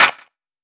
Chart Clap 01.wav